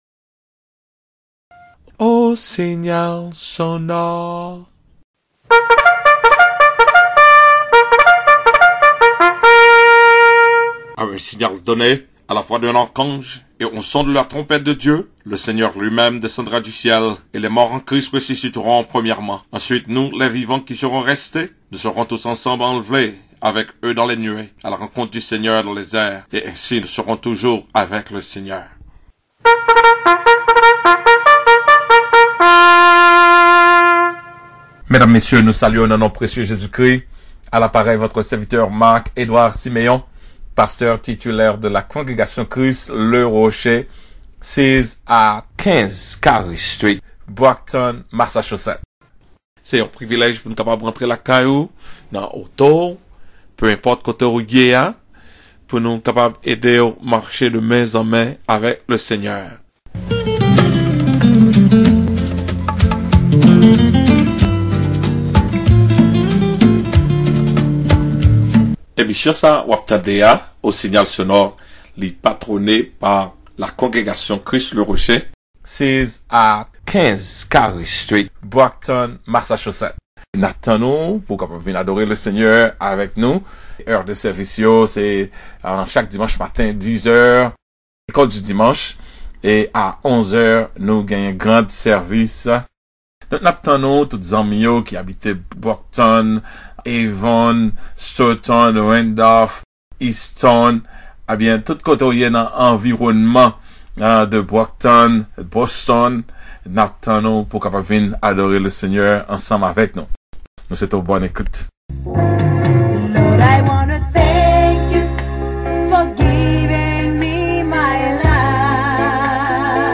JOSEPH PARDONNE ET CONSOLE SES FRERES DE L’AVOIR VENDU EN ESCLAVAGE CLICK ABOVE TO DOWNLOAD THE SERMON